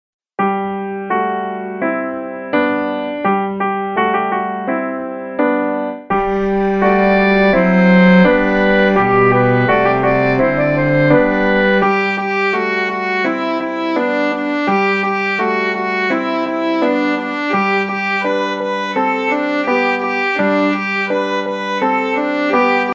"Ходить Гарбуз по городу" мінус